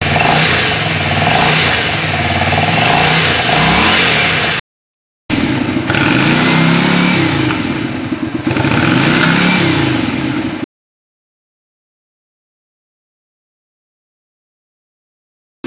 first clip, my bike stock, second part is with the rear most baffle out a 1.5" hole drilled with a hole saw.
First baffle modified